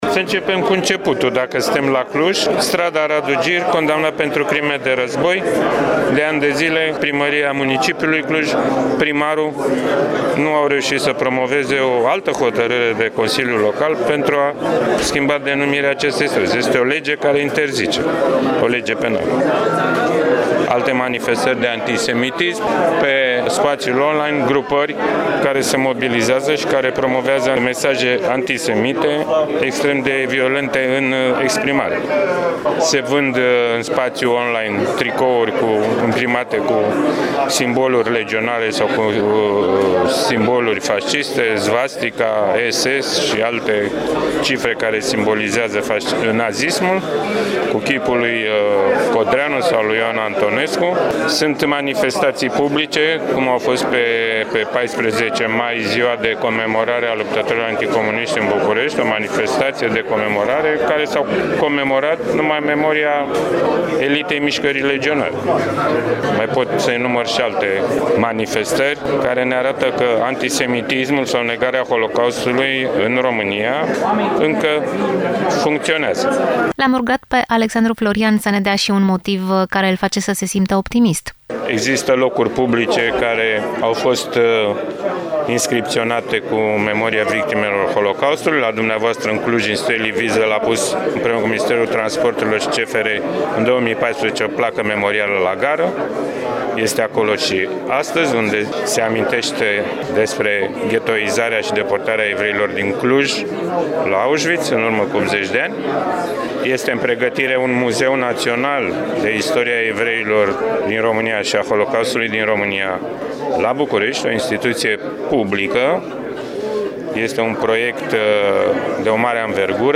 Conferință internațională dedicată Holocaustului din Transilvania de Nord.